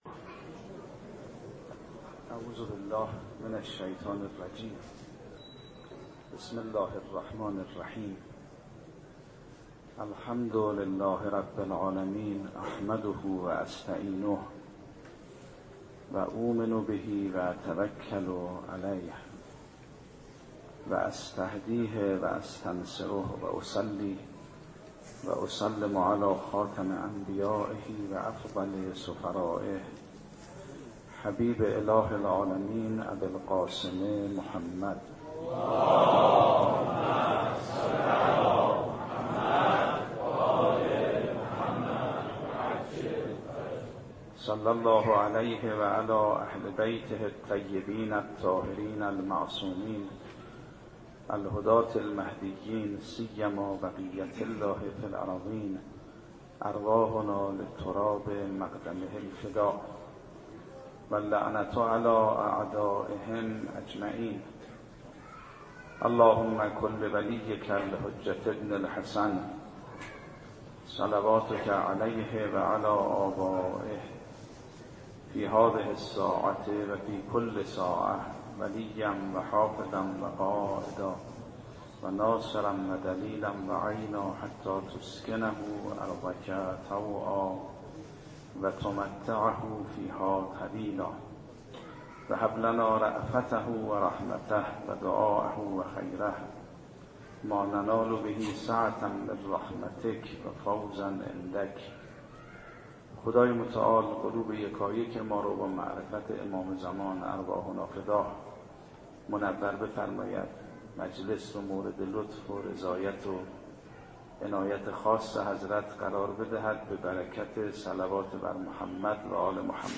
شب اول محرم 96 - هیئت ثار الله - سخنرانی